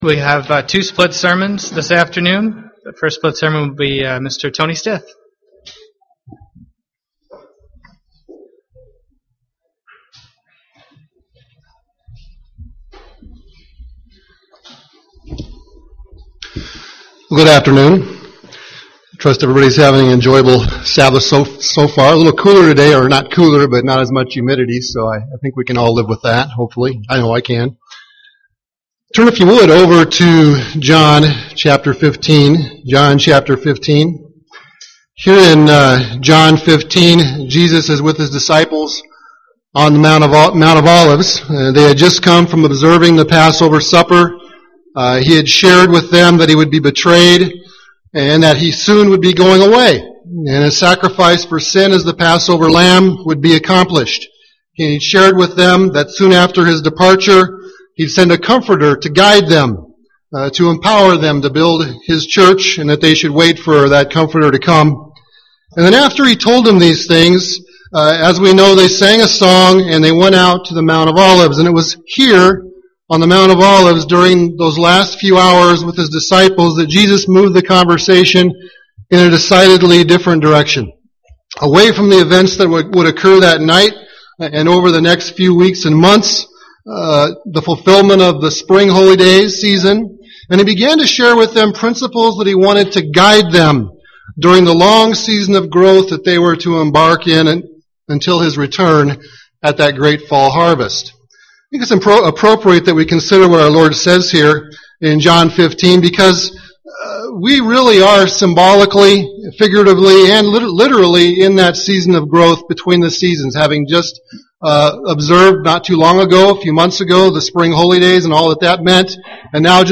Given in Twin Cities, MN
Print Principles for experiencing abundant, thriving spiritual growth from John 15 UCG Sermon Studying the bible?